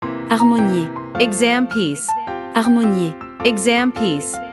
Professional-level Piano Exam Practice Materials.
• Vocal metronome and beats counting
• Master performance examples